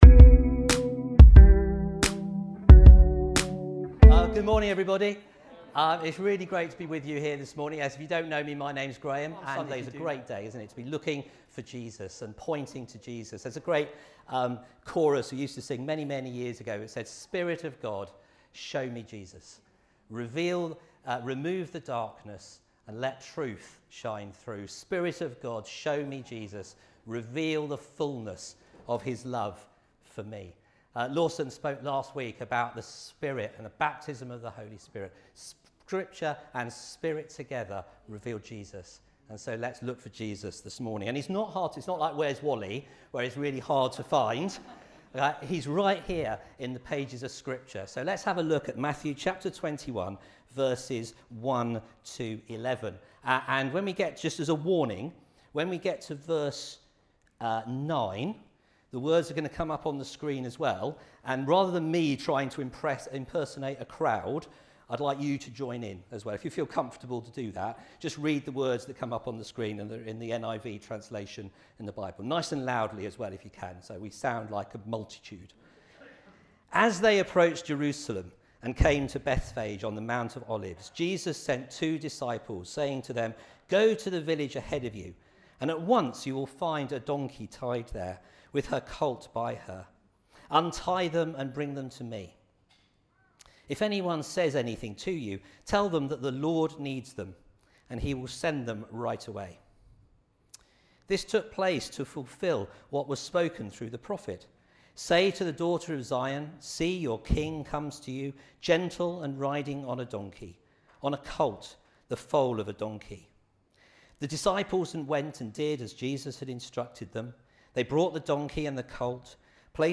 Rediscover Church Newton Abbot | Sunday Messages Who Do You Follow?